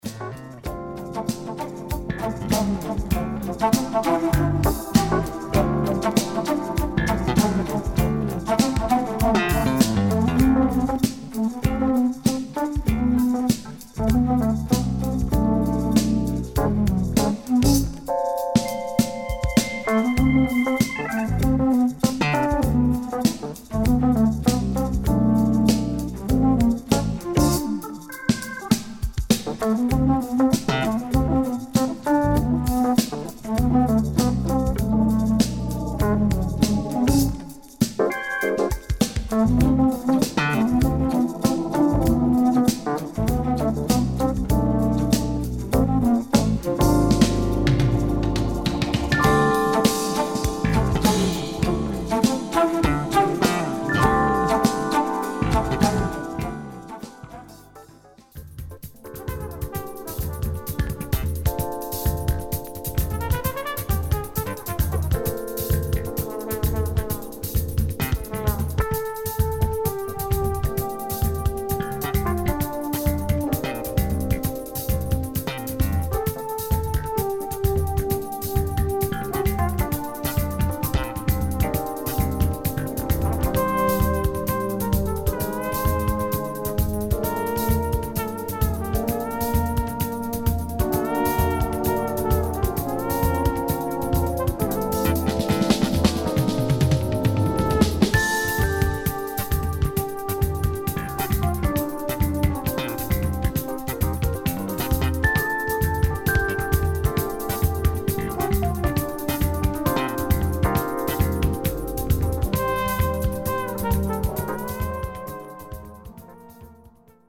Brazilian groove